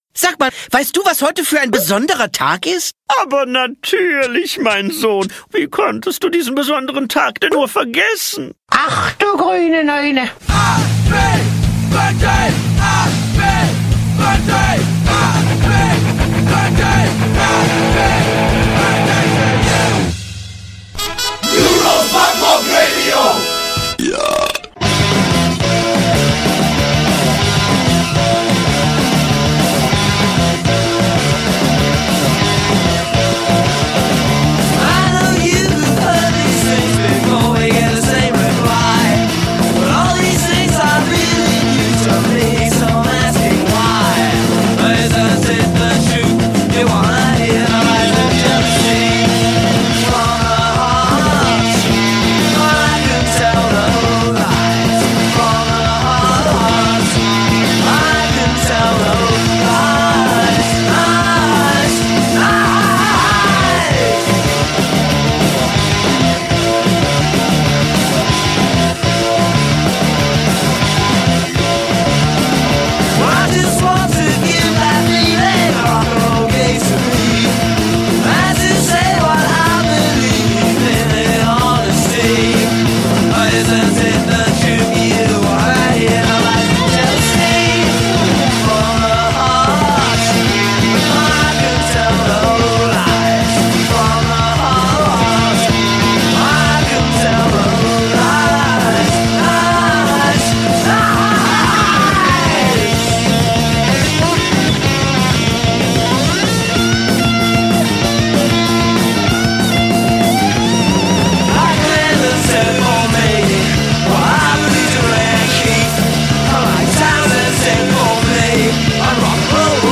Powersounds